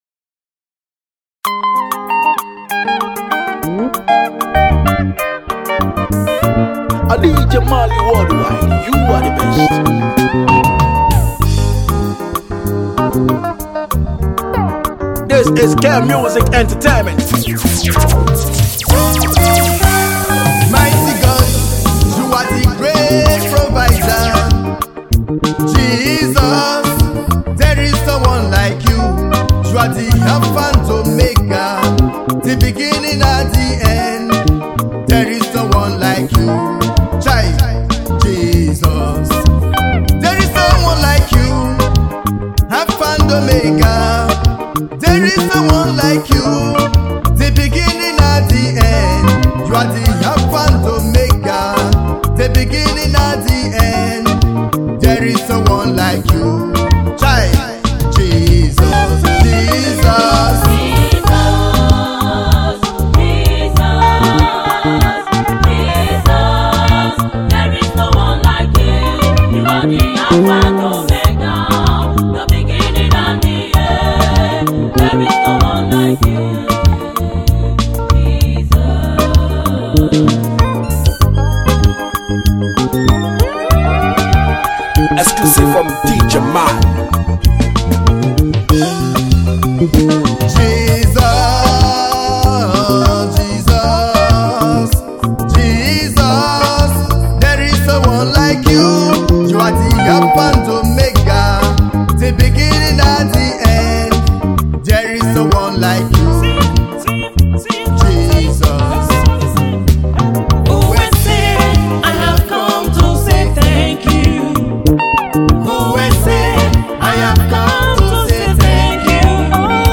Gospel Mixtape
a powerful gospel Mixtape
With a blend of soulful worship and uplifting melodies